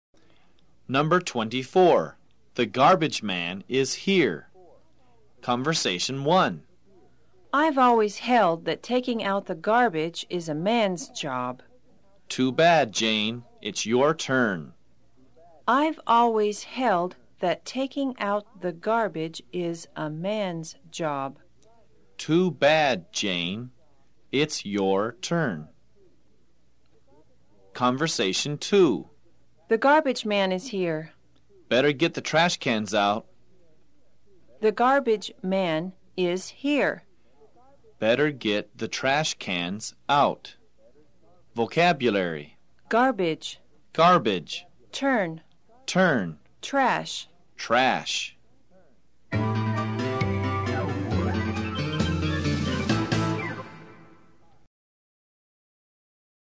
在线英语听力室快口说英语024的听力文件下载,快口说英语的每一句话都是地道、通行全世界的美国英语，是每天24小时生活中，时时刻刻都用得上的美语。